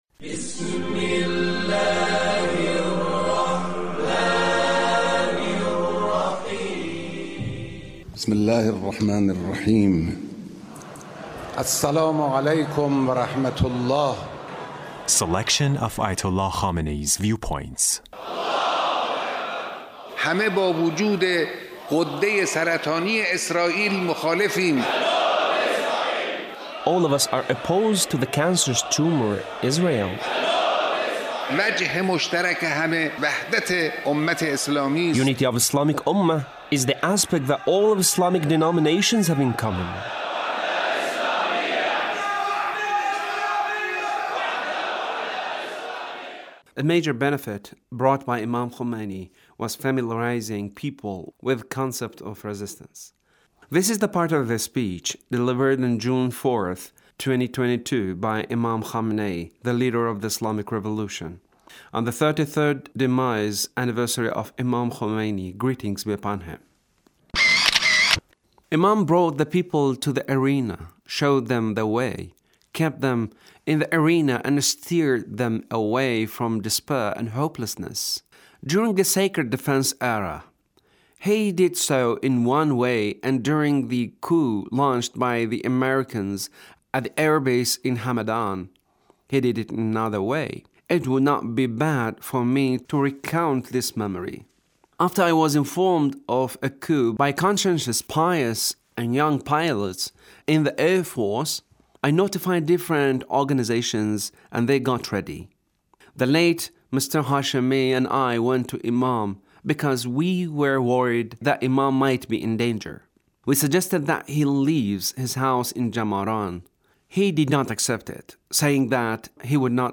The Leader's speech on The Demise Anniversary of The Imam